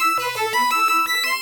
Index of /musicradar/shimmer-and-sparkle-samples/170bpm
SaS_Arp03_170-A.wav